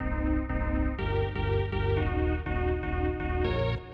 AIR Airport Stab Riff B-C.wav